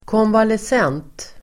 Ladda ner uttalet
Uttal: [kånvales'en:t]